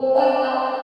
64 GUIT 6 -R.wav